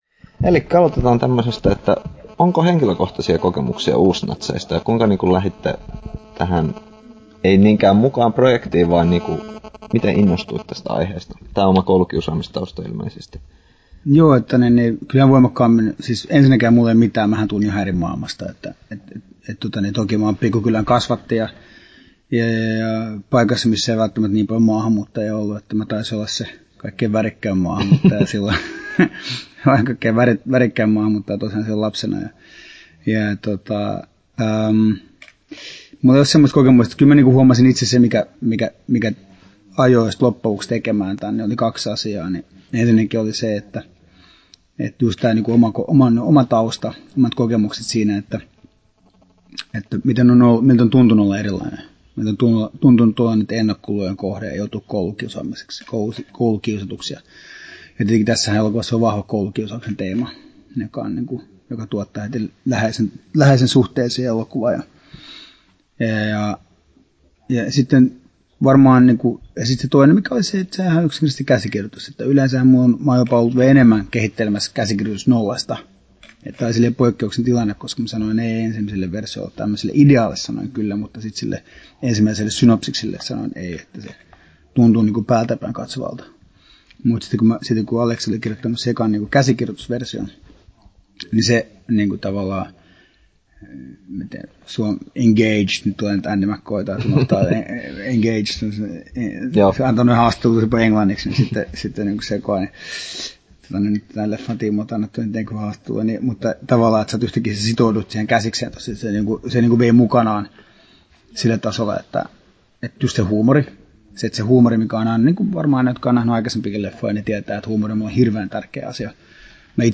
Dome Karukosken haastattelu Kesto